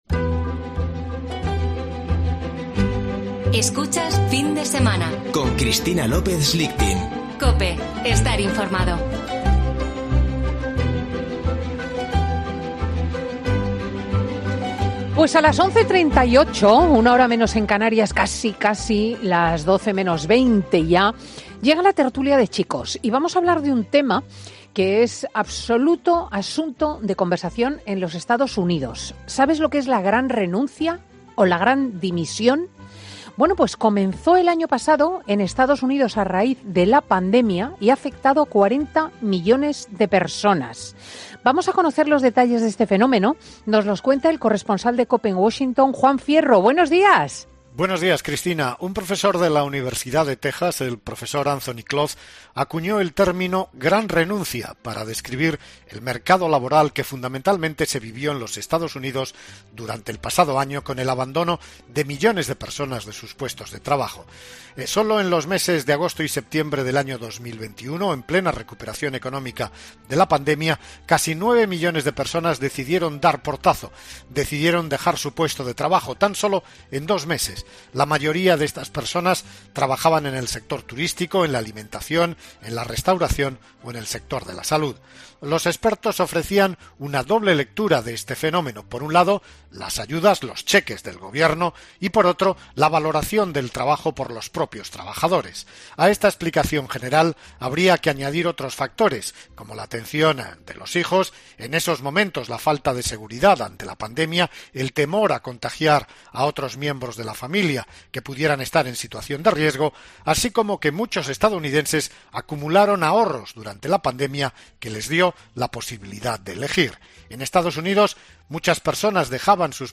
Tertulia de chicos en Fin de Semana con Cristina